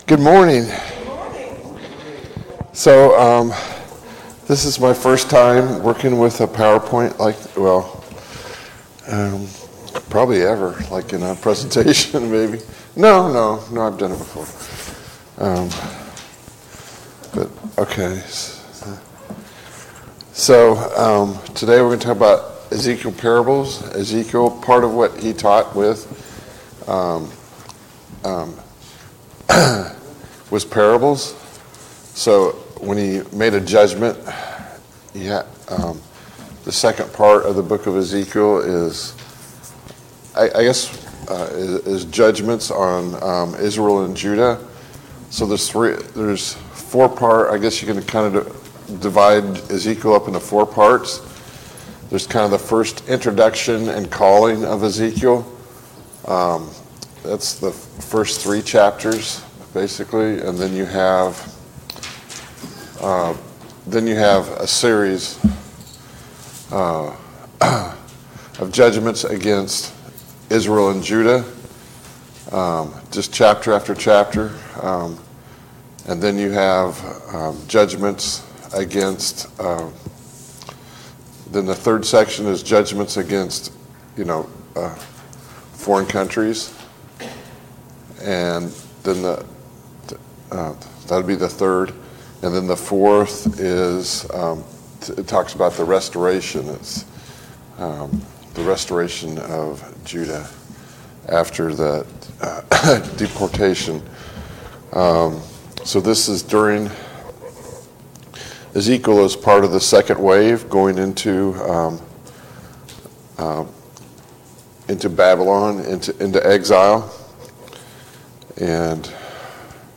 Study of Ezekiel Service Type: Sunday Morning Bible Class « Study of Paul’s Minor Epistles